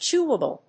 音節chéw・a・ble
アクセント・音節chéw・a・ble